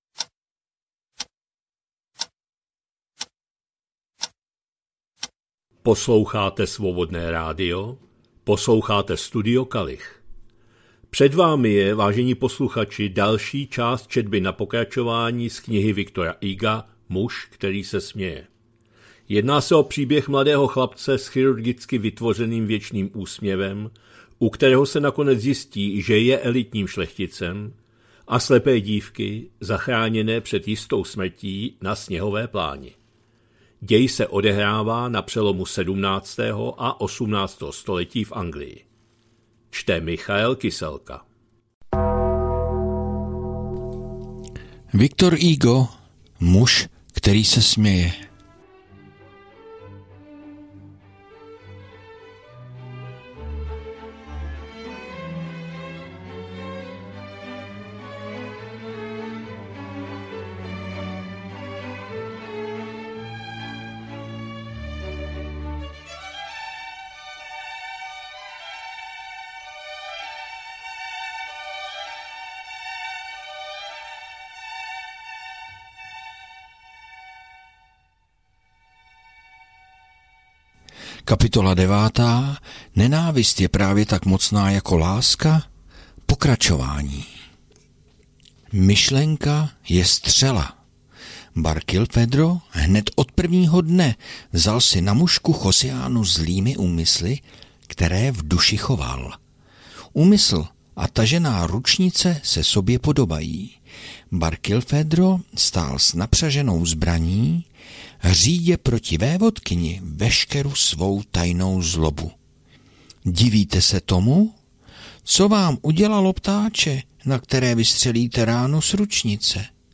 2025-08-18 – Studio Kalich – Muž který se směje, V. Hugo, část 24., četba na pokračování